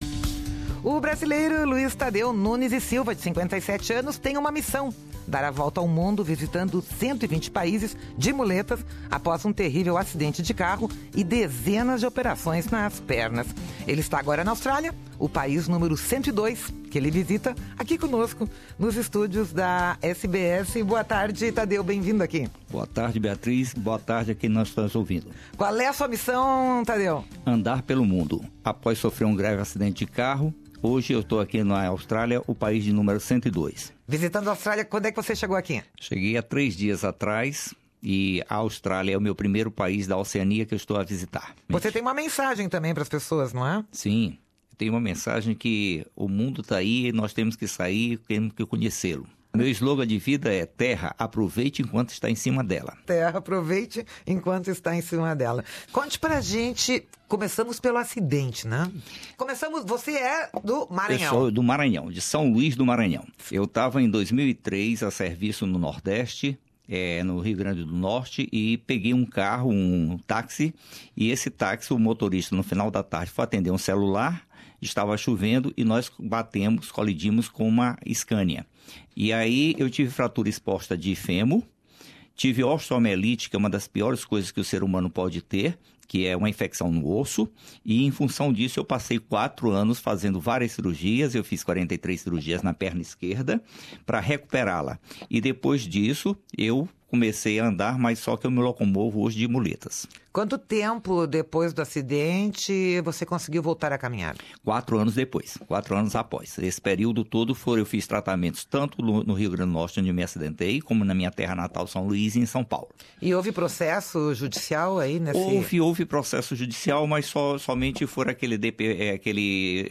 nos estúdios da Rádio SBS em Sydney